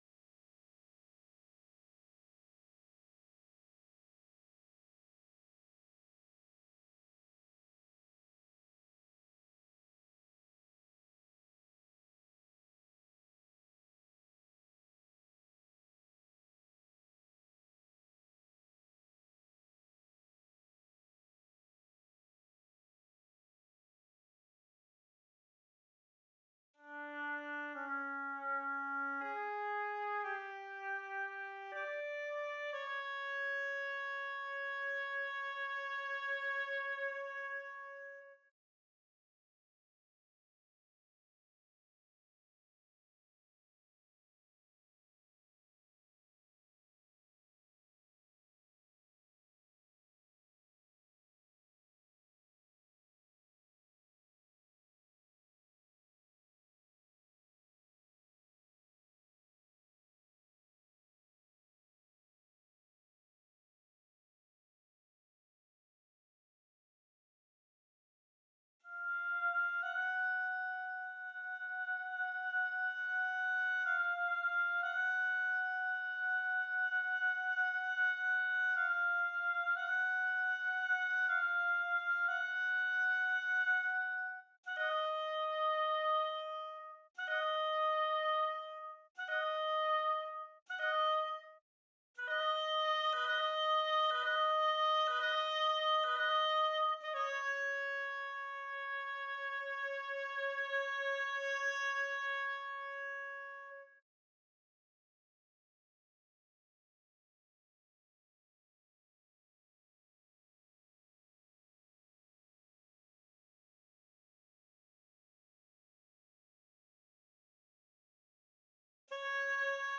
3. Oboe (Oboe/Normal)
Holst-Mars-35-Oboe_0.mp3